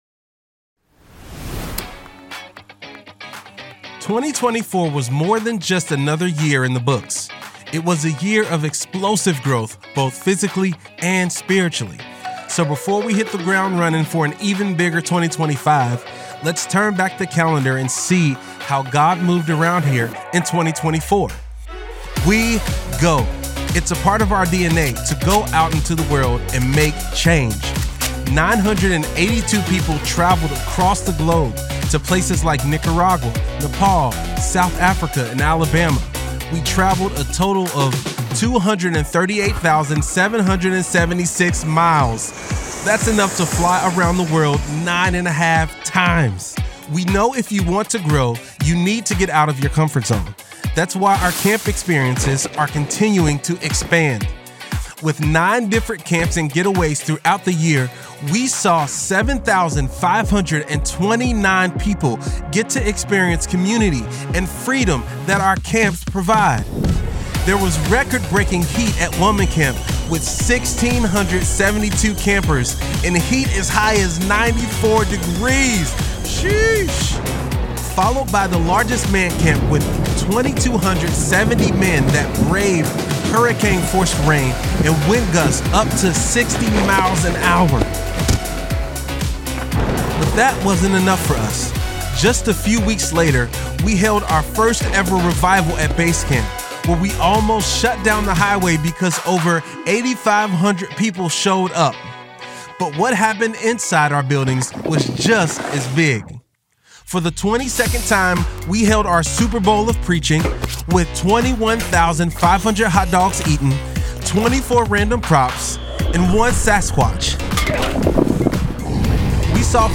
an exclusive online message about looking forward to 2025 and the power of bold prayers.